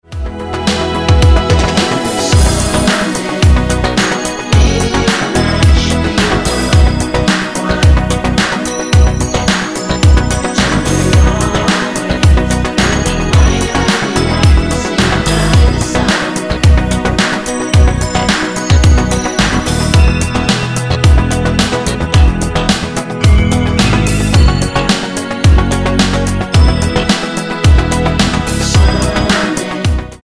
Tonart:B mit Chor